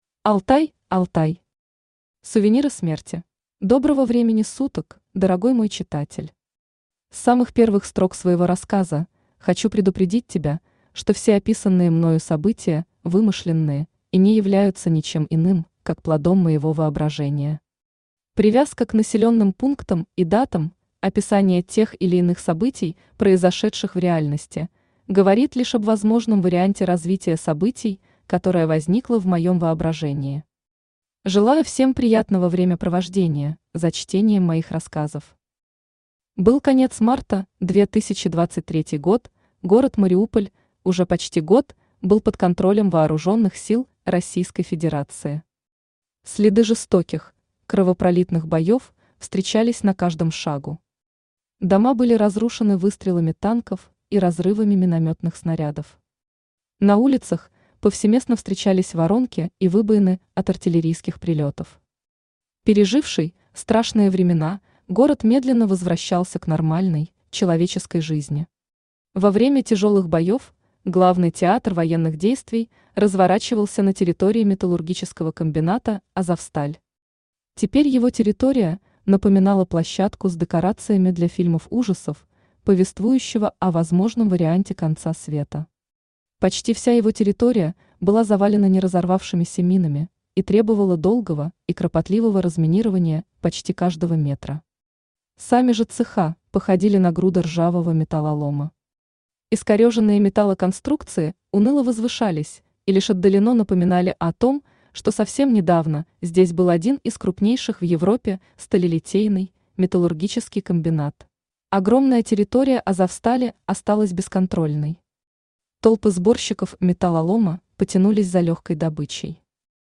Аудиокнига Сувениры смерти | Библиотека аудиокниг
Aудиокнига Сувениры смерти Автор Алтай Алтай Читает аудиокнигу Авточтец ЛитРес.